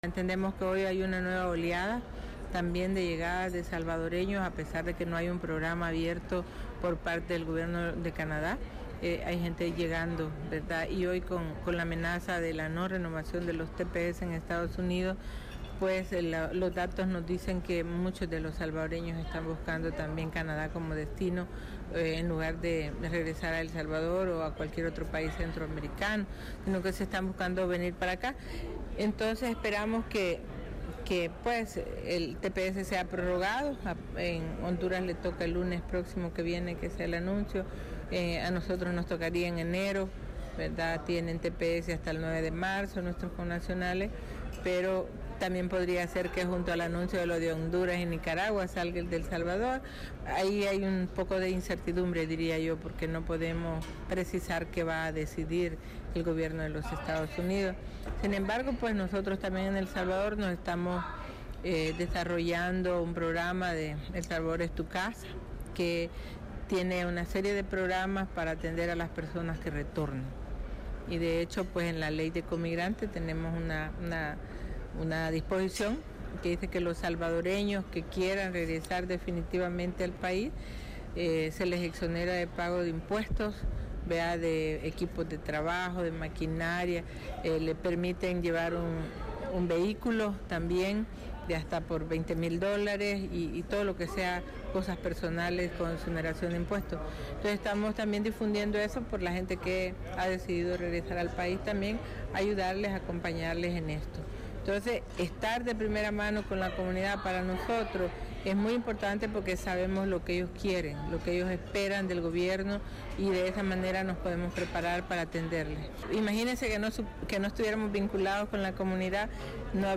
La licenciada Liduvina Magarín, Viceministra responsable de los salvadoreños en el exterior, dice en entrevista con Radio Canadá Internacional que hay alrededor de 3 millones de salvadoreños viviendo fuera del país.